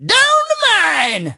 tnt_guy_start_vo_04.ogg